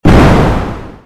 scsm_explosion3w.wav